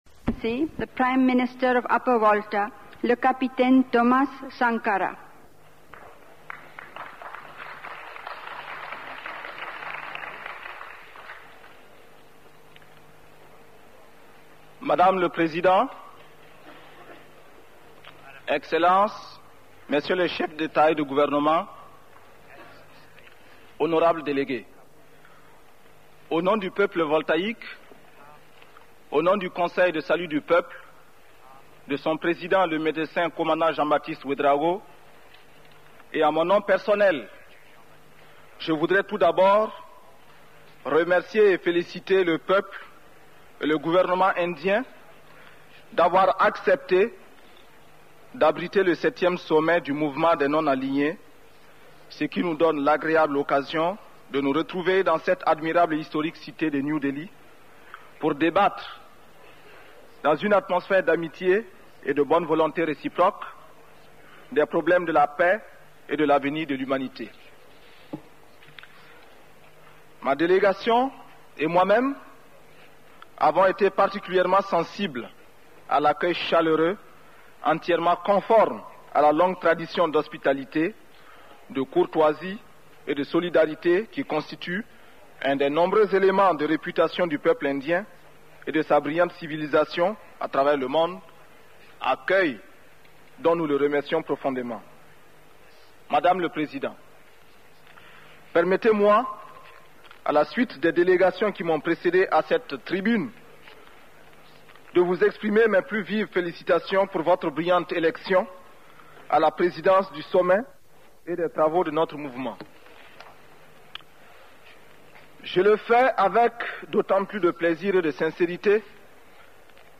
Discours prononcé au Sommet des Non alignés de New Delhi en mars 1983 - Thomas Sankara Website - Officiel
Ci-dessous le texte du discours